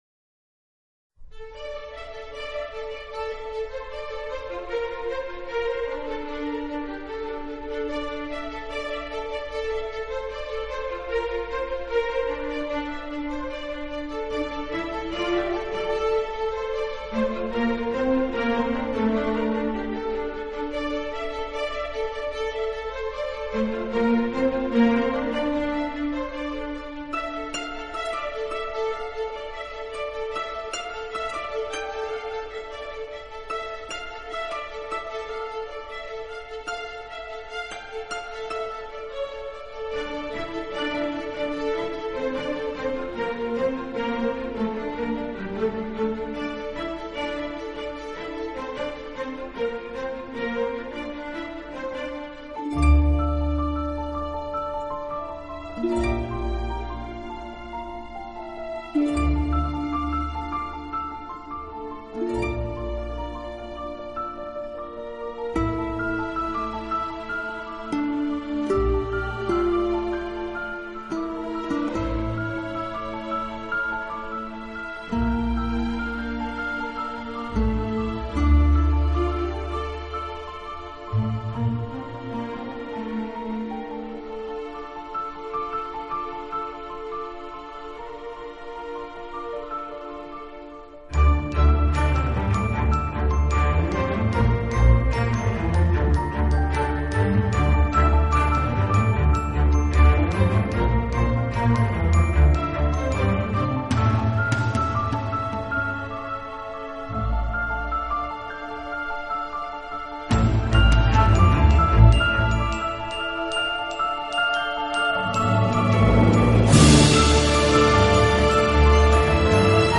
【新世纪】
【新世纪音乐】